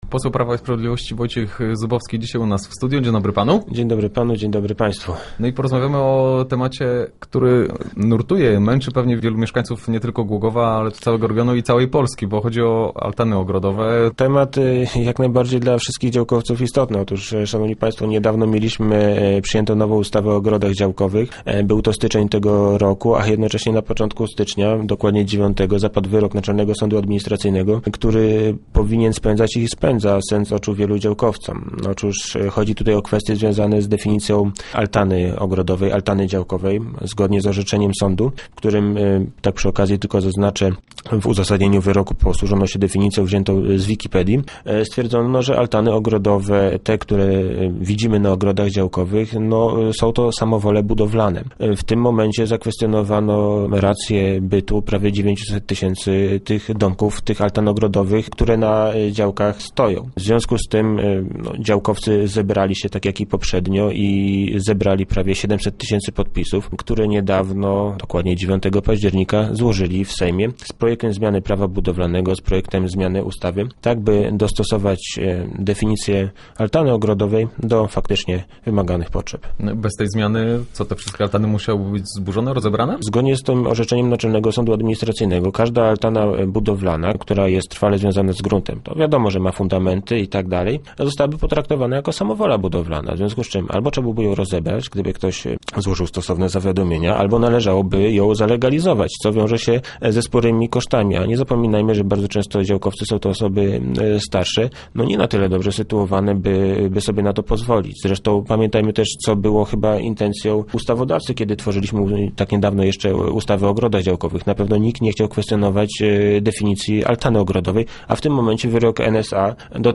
Start arrow Rozmowy Elki arrow Zubowski: Działkowcom trzeba pomóc